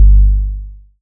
R.G.G. BASS.wav